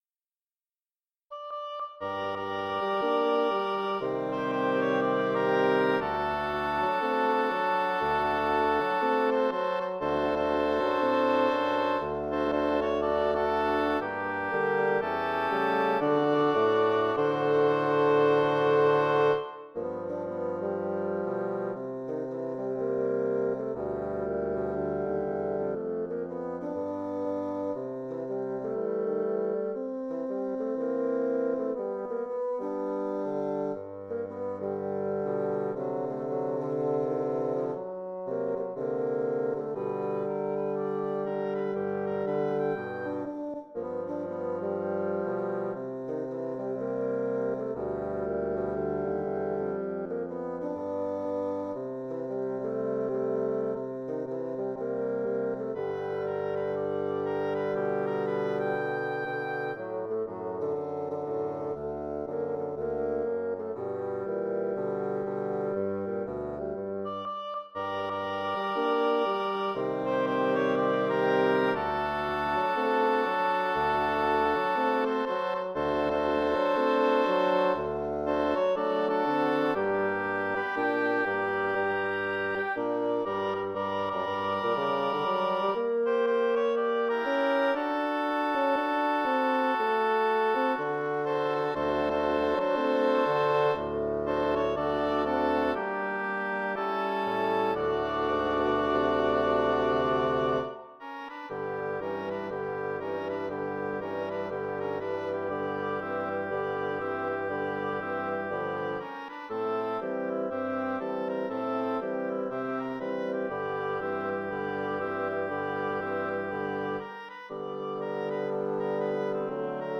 bassoon music , double reed , english horn , oboe music